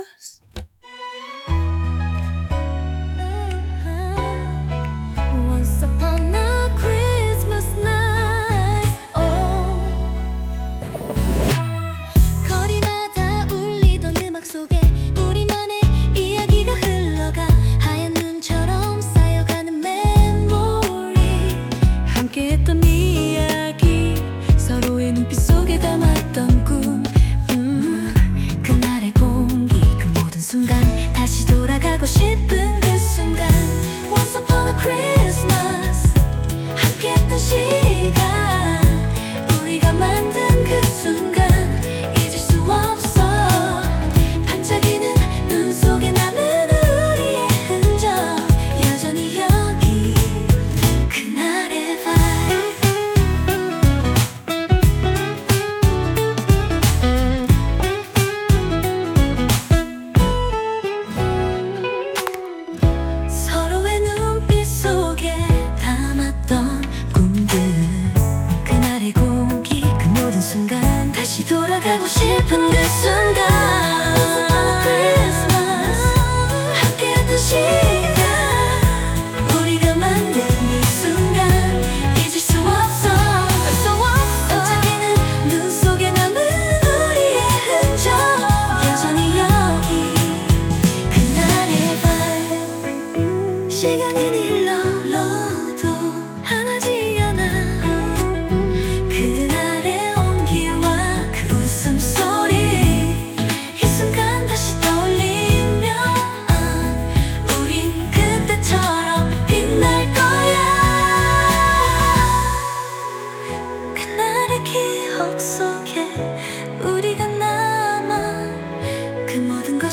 3. 출력 결과 분석: 생성된 노래가 제 실제 목소리와 유사하면서도 미묘한 차이가 있는 것을 확인했습니다.
• 수노에서 생성된 곡은 마스터링이 완료된 형태로 제공된다는 점을 알게 되었습니다.
• AI가 생성한 보컬은 자연스러우면서도 미묘한 인공적인 느낌이 남아 있었습니다.